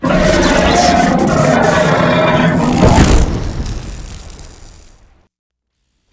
combat / ENEMY / droid / bigdie2.wav
bigdie2.wav